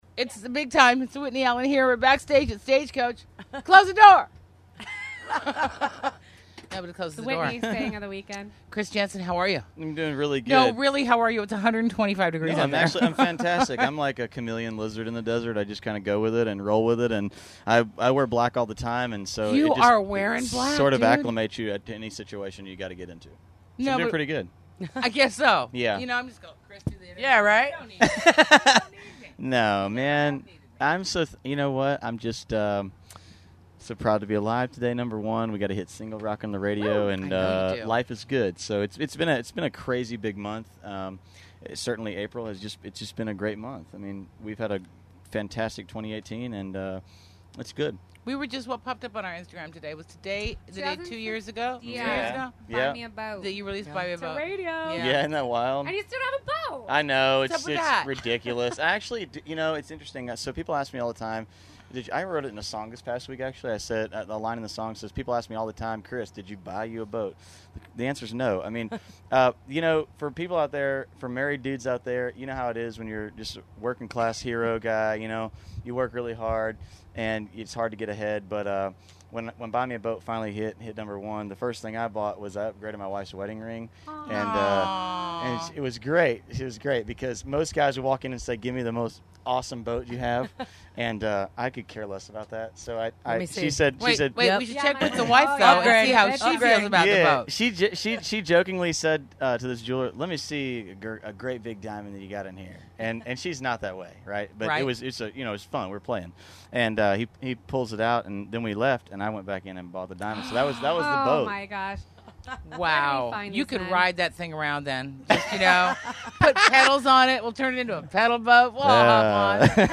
Chris Janson Interview From The Stagecoach Music Festival! - The Big Time with Whitney Allen
The Big Time with Whitney Allen talks with Chris Janson at the 2018 Stagecoach Music Festival, check it out: